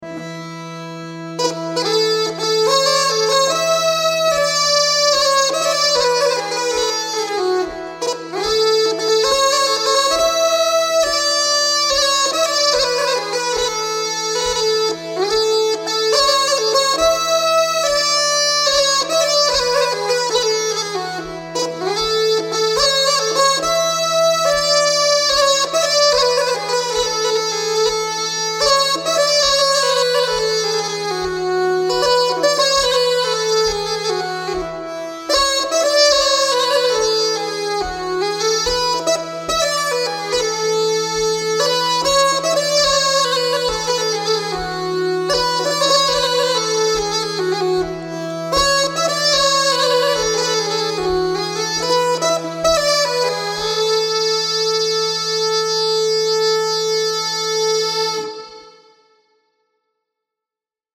12 Marsch “Päbel-Brittas bufärdsmarsch” (Svensk säckpipa)